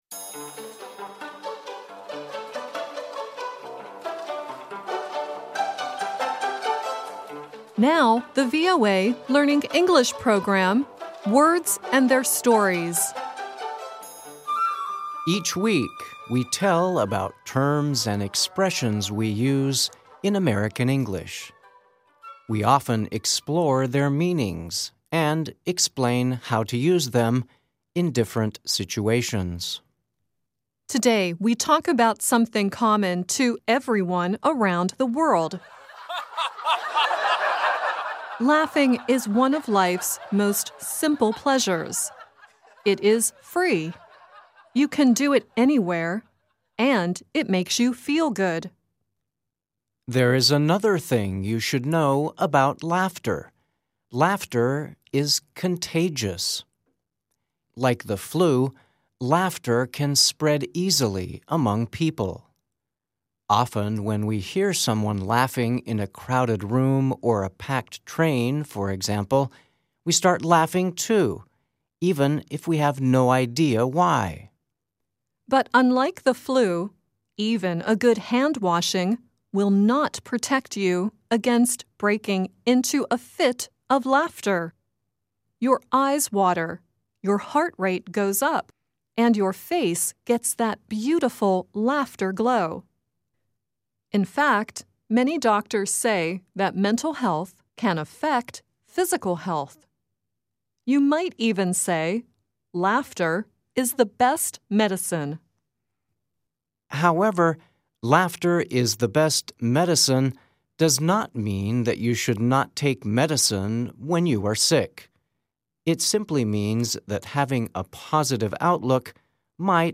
The song at the end is the song "I Love to Laugh" from the film Mary Poppins.